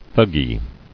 [Thug·gee]